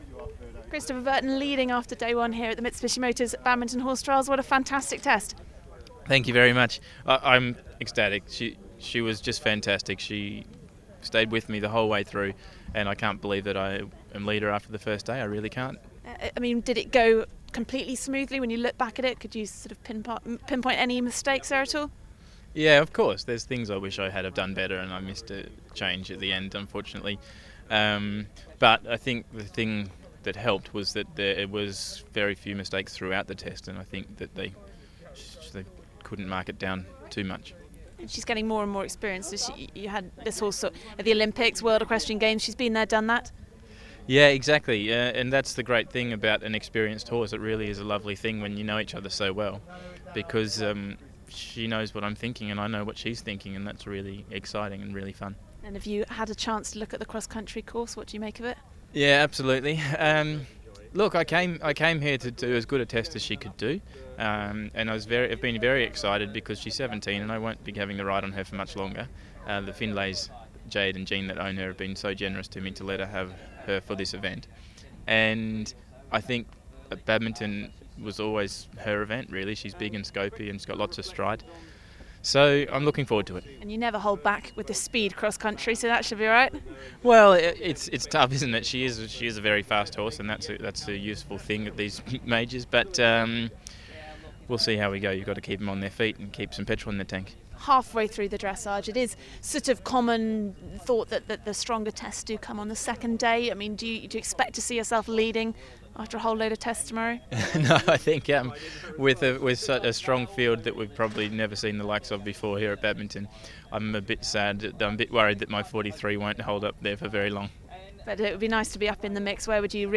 Interview with Chris Burton after dressage